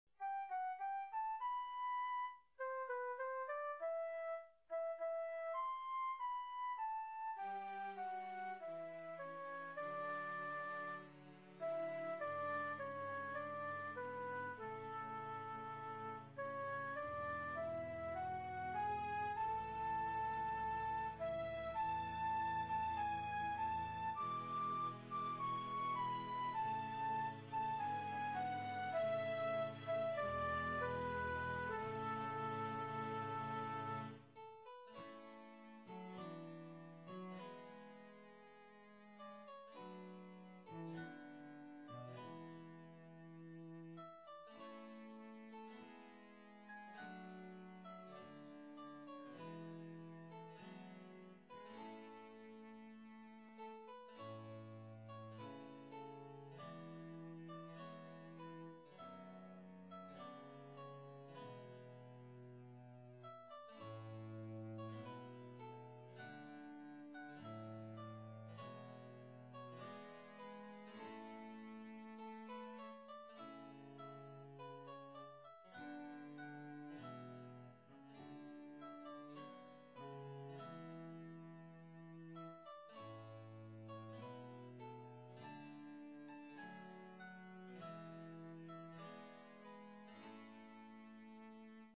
Everything is made in MIDI which gives a thinner sound.
soprano voice in the harpsichord